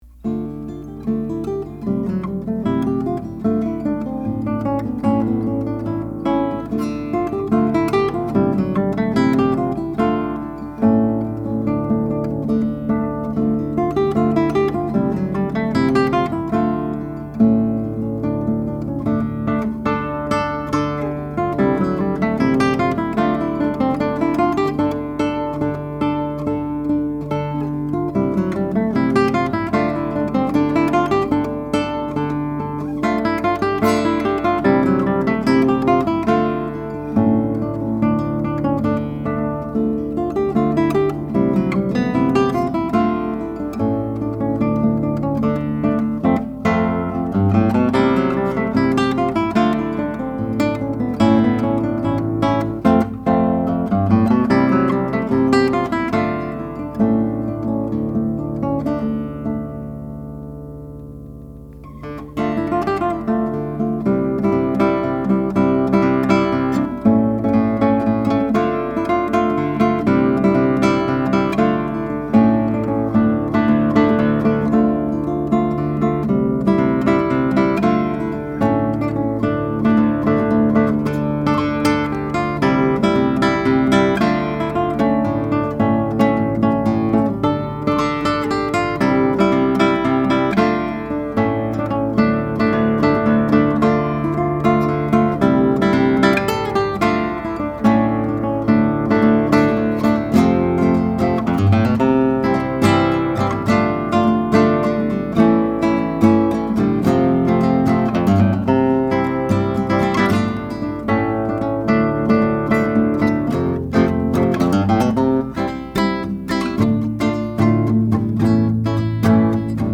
Here are 19 very quick, 1-take MP3 sound files of me playing this guitar, to give you an idea of what to expect. The guitar has amazing sympathetic resonance and sustain, as well as good power and projection, beautiful bass responce, and a very even response across the registers. These MP3 files have no compression, EQ or reverb -- just straight signal, tracked through a Blue Woodpecker ribbon mic, into a Presonus ADL 600 mic preamp, into a Sony PCM D1 flash recorder.